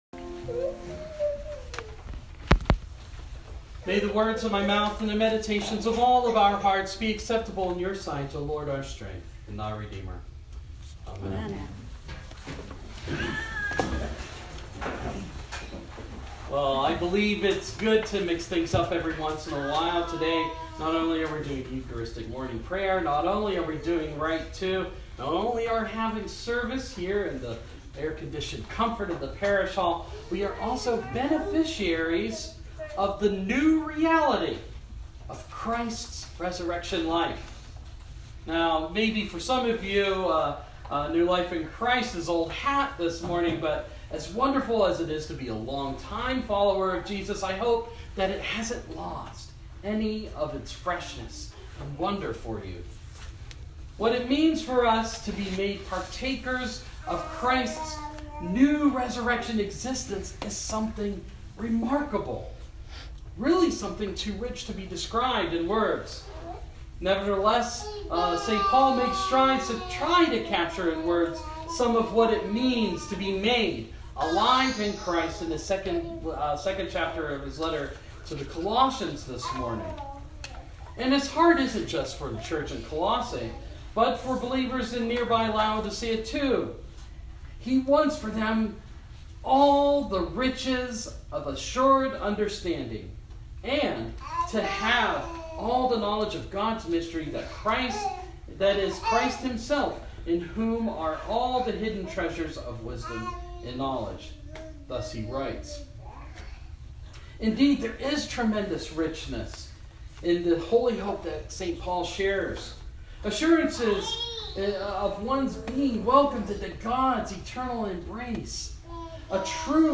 Sermon – Proper 12 – 2016